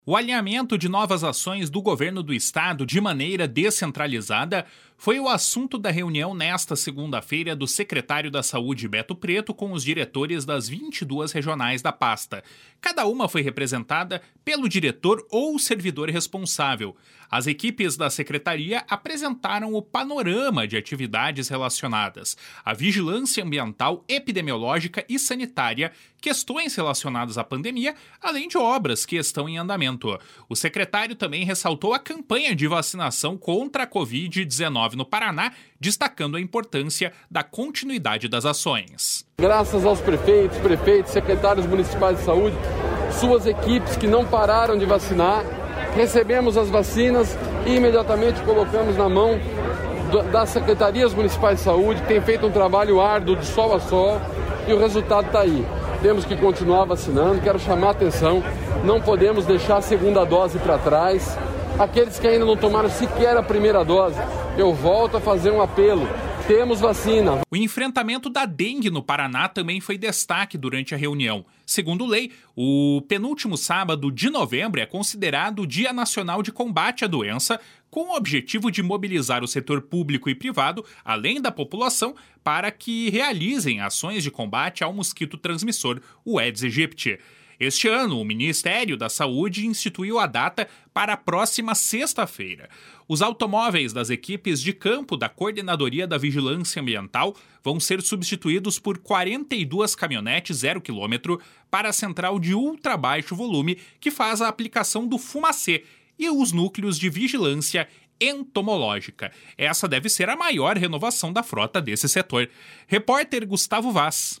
O secretário também ressaltou a campanha de vacinação contra a Covid-19 no Paraná, destacando a importância da continuidade das ações. // SONORA BETO PRETO //